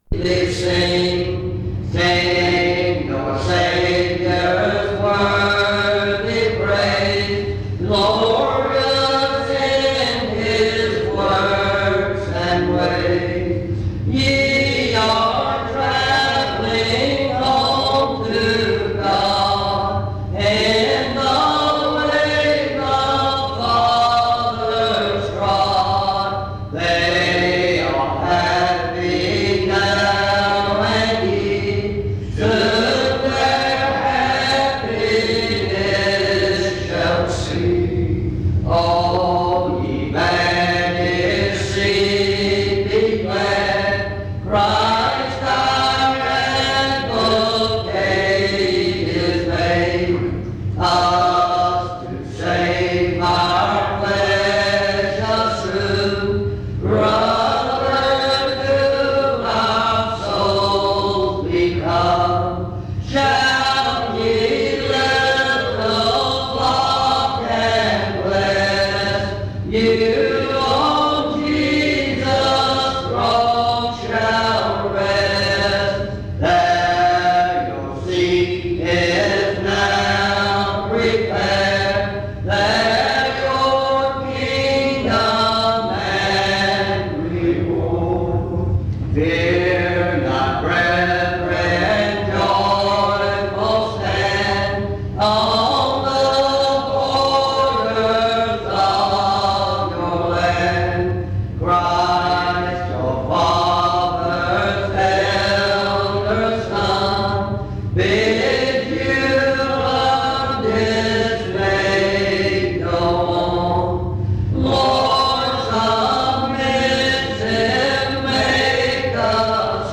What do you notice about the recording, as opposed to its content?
In Collection: Reidsville/Lindsey Street Primitive Baptist Church audio recordings Thumbnail Titolo Data caricata Visibilità Azioni PBHLA-ACC.001_065-B-01.wav 2026-02-12 Scaricare PBHLA-ACC.001_065-A-01.wav 2026-02-12 Scaricare